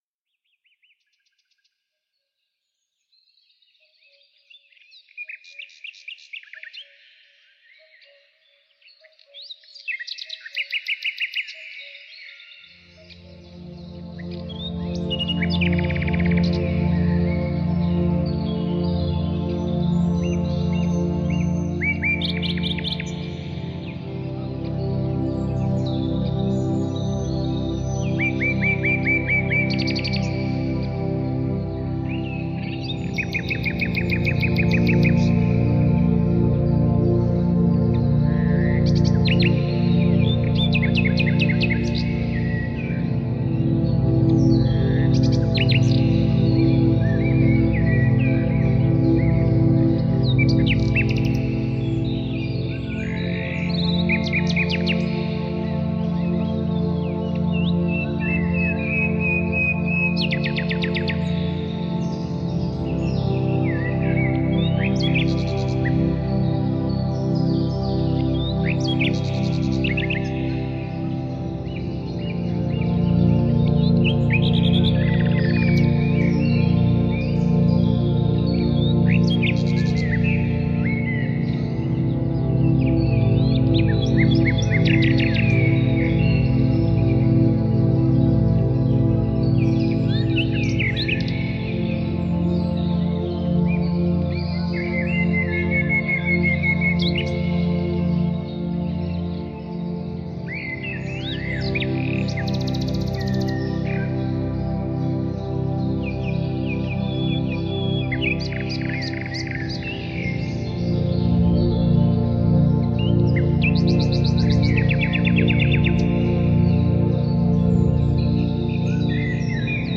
nature sounds and atmospheric sounds, perfect for relaxation
ambient
new age